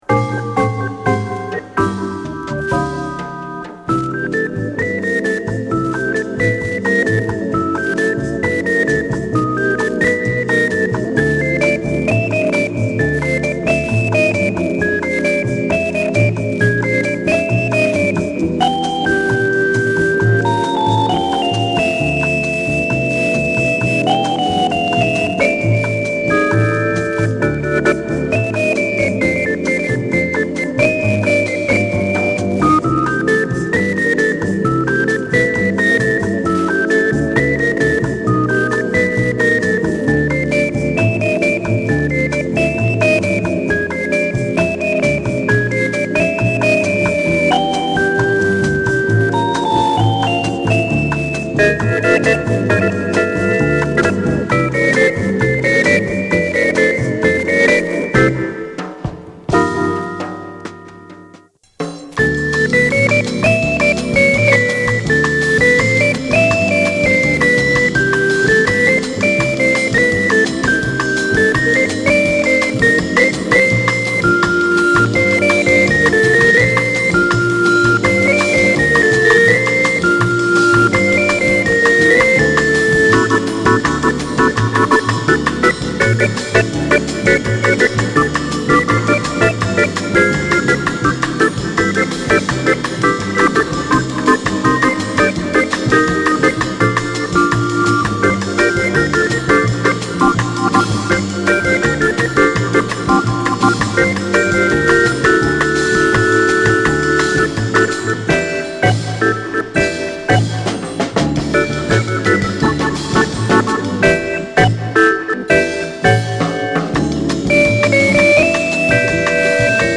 ブラジル出身のピアノ/オルガン奏者
軽快なリムショットにオルガンが軽やかに乗る